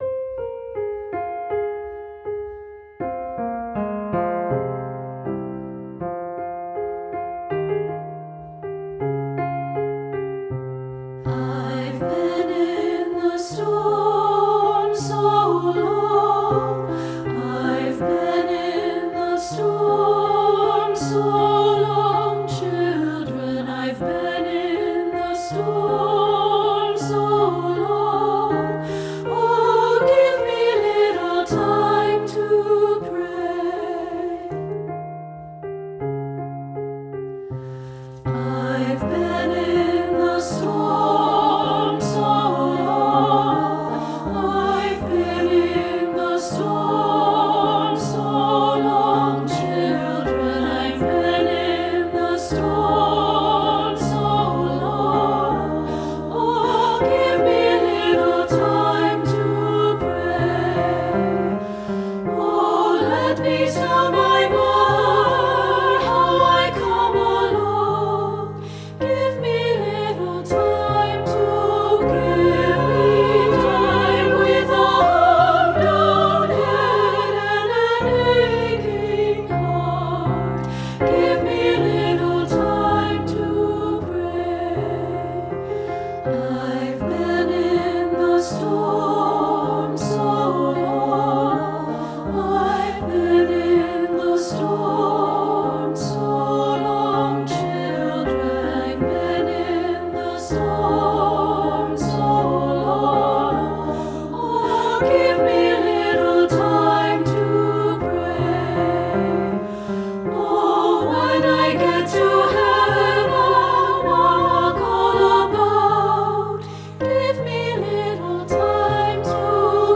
Traditional Spiritual
Voicing: SA a cappella